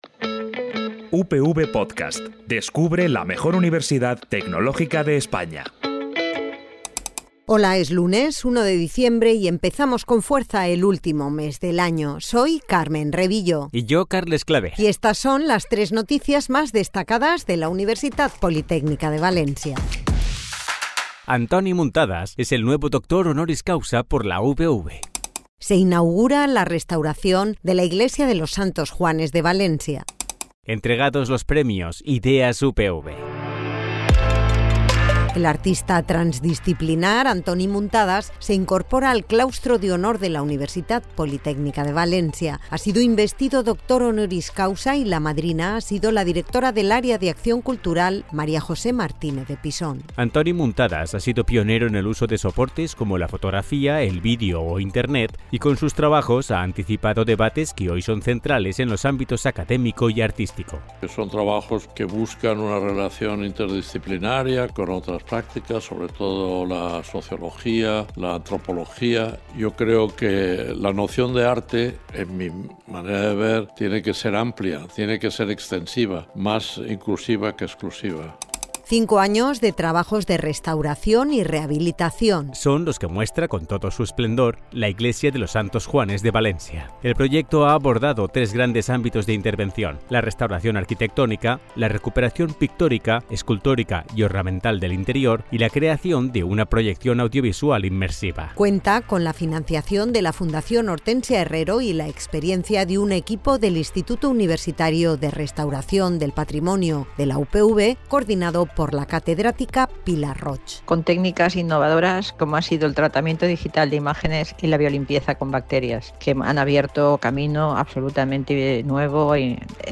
És la versió sonora del butlletí informatiu per a informar-te del que passa a la Universitat Politècnica de València.